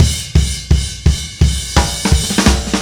Indie Pop Beat 05 Fill B.wav